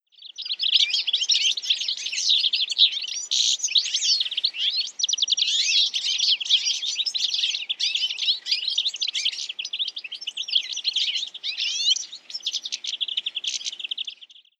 European Goldfinch
Carduelis carduelis